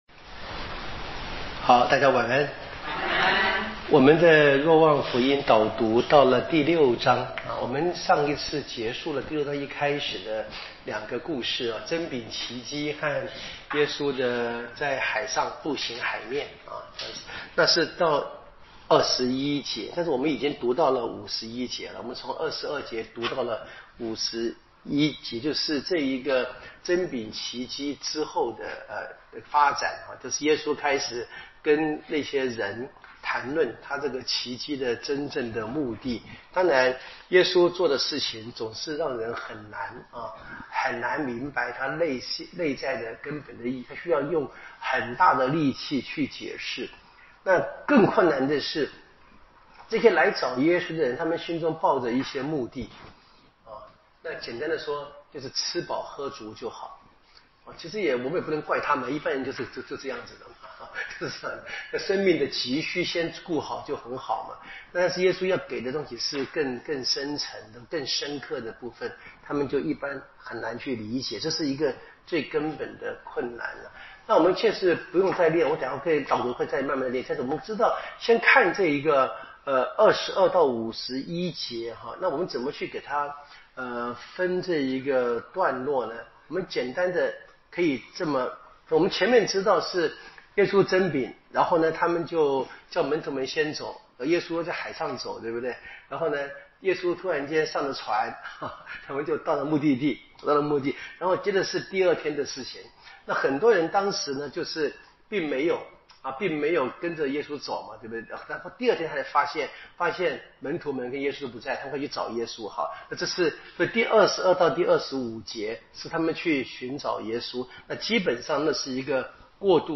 【圣经讲座】《若望福音》